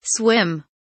swim kelimesinin anlamı, resimli anlatımı ve sesli okunuşu